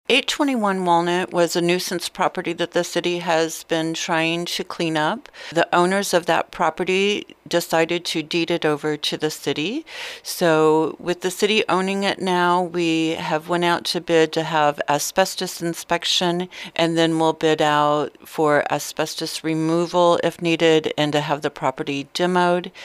A property at 821 Walnut Street was turned over to the City of Chillicothe.  City Administrator Roze Frampton explains.